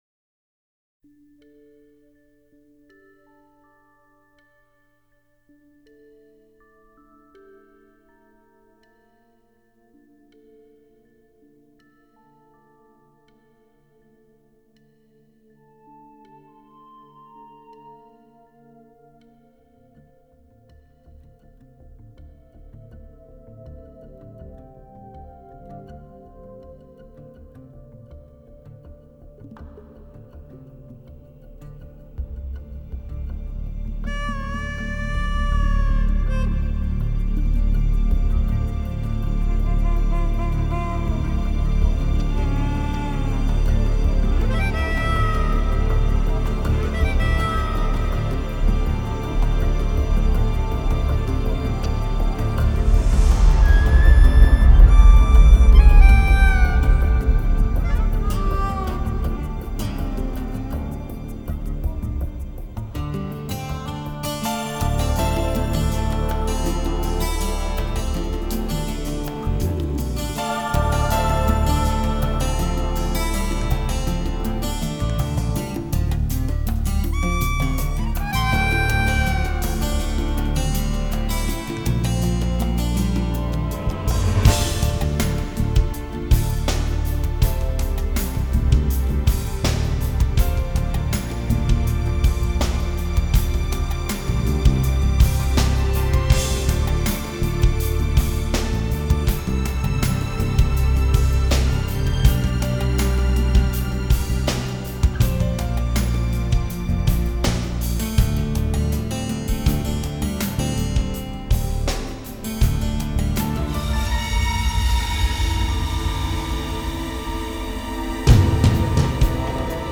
BSO